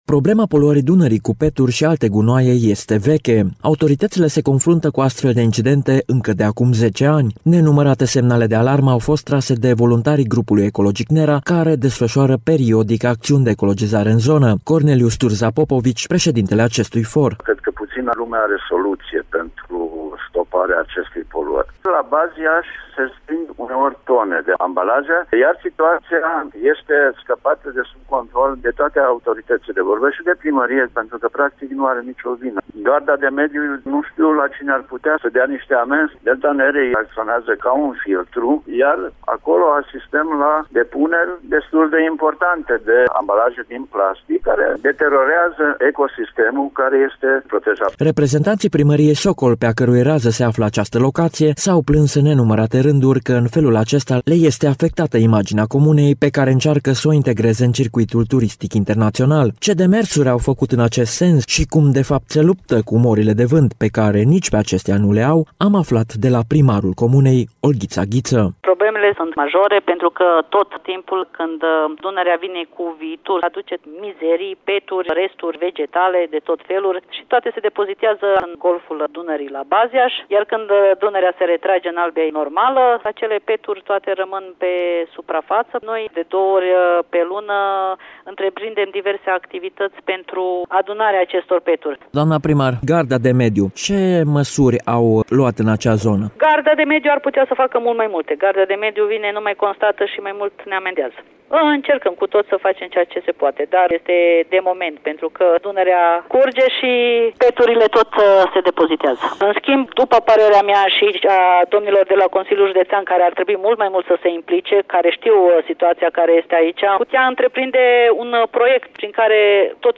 Reprezentanţii Primăriei Socol, pe a cărui rază se află Golful Nerei, s-au plâns în mai multe rânduri că în felul acesta le este afectată imaginea comunei, pe care încearcă să o integreze în circuitul turistic internaţional, spune primarul comunei, Olghiţa Ghiţă.